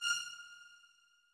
GAR String F.wav